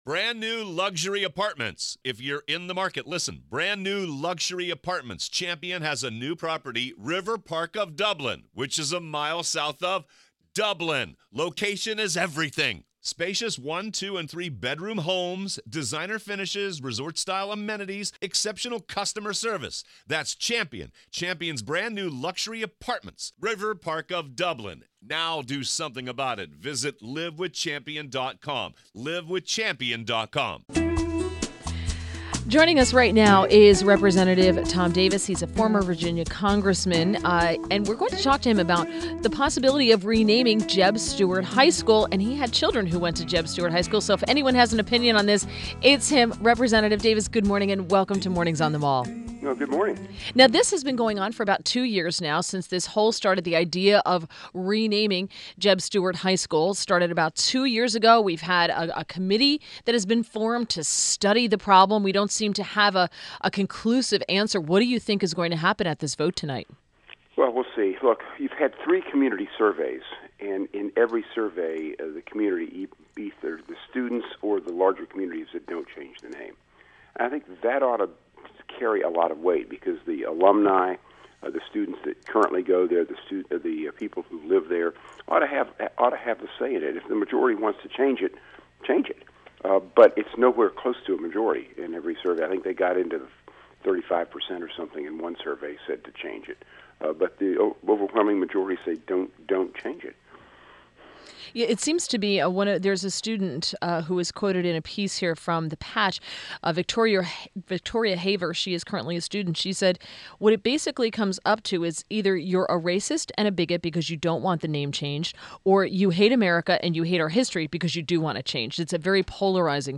INTERVIEW – REP TOM DAVIS — former Virginia congressman, he had children who went to JEB Stuart High